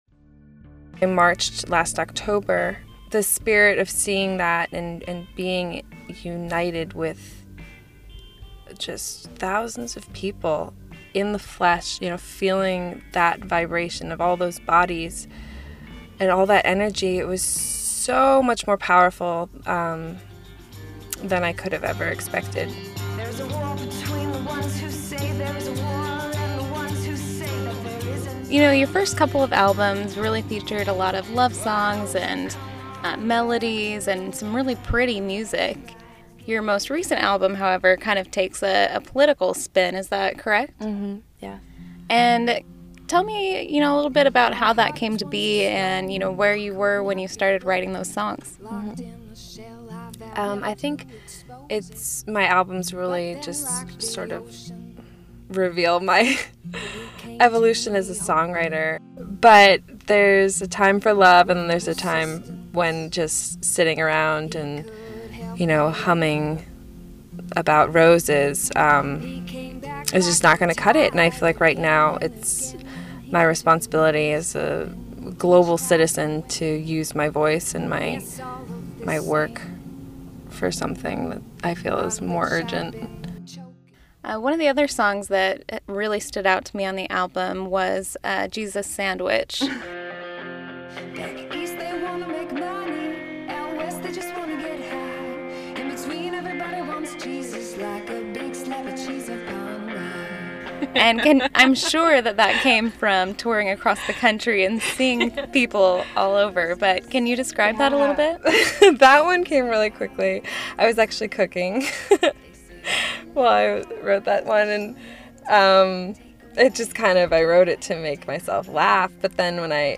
Music_interview_0.mp3